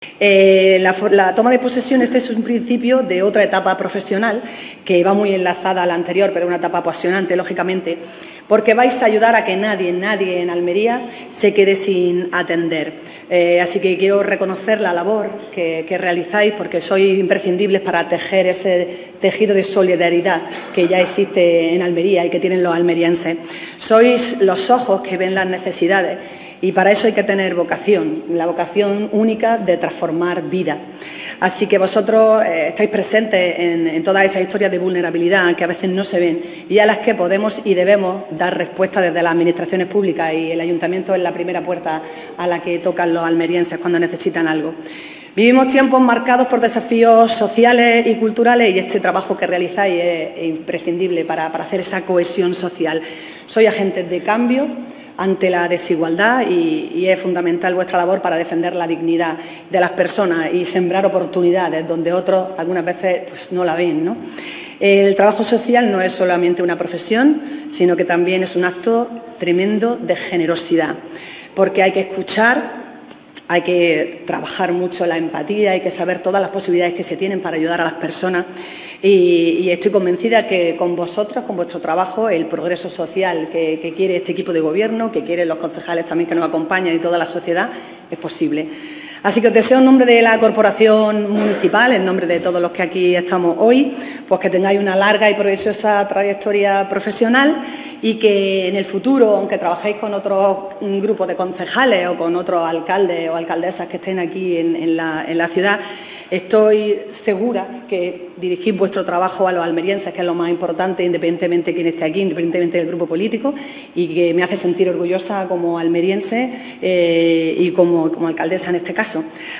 María del Mar Vázquez, que ha presidido el acto celebrado en el Salón de Plenos, les ha deseado “una larga y provechosa trayectoria de trabajo por Almería y por los almerienses”